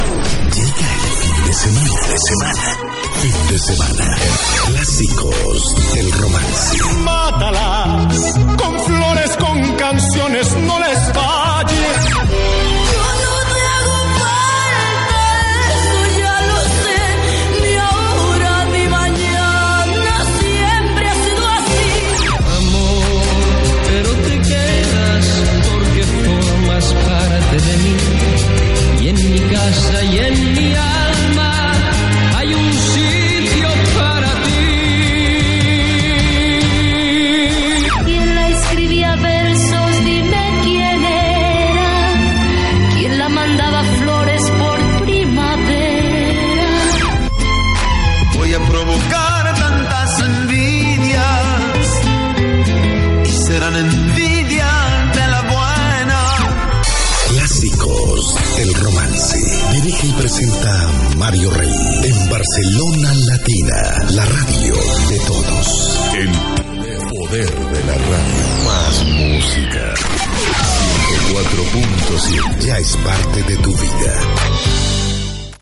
Promoció del programa i identificació de la ràdio